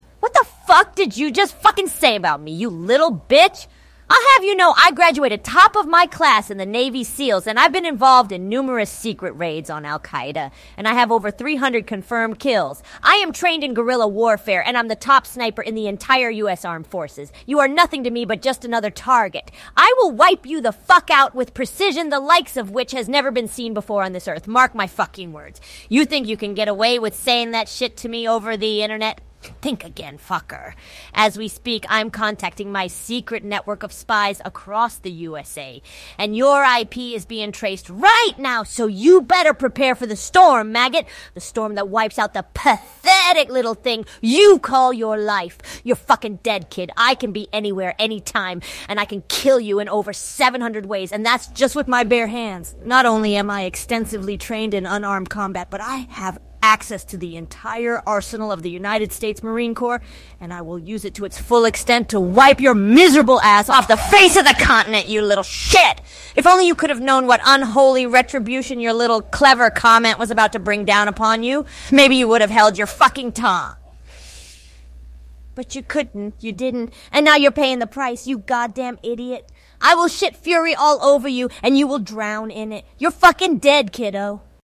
AI generated audio